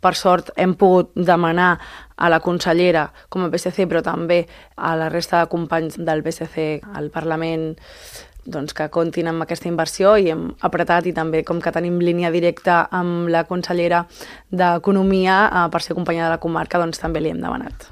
El PSC de Calella també fa pressió perquè el Govern de Salvador Illa inclogui l’ampliació del Sant Jaume en el projecte de pressupostos per al 2025. Cindy Rando ha explicat a l’entrevista política de Ràdio Calella TV que s’ha demanat a la consellera de Salut, Olga Pané, i a la d’Economia i Finances, la mataronina Alícia Romero.